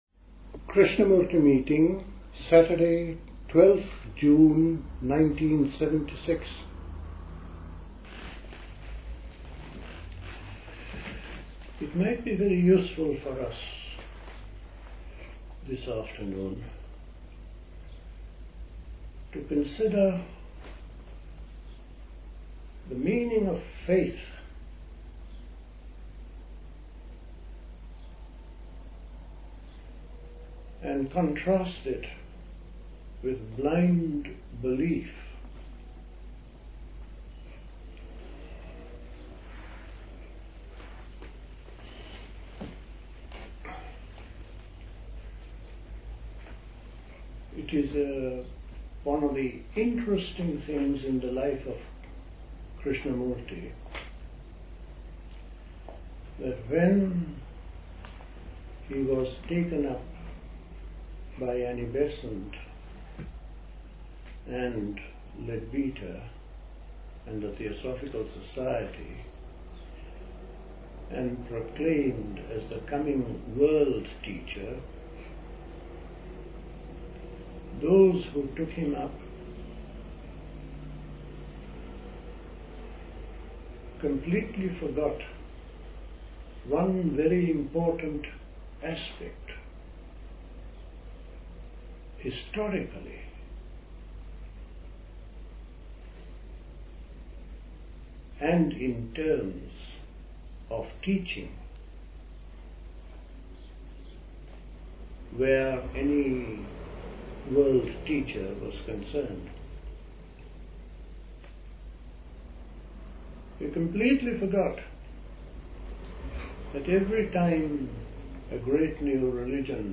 Recorded at a Krishnamurti meeting.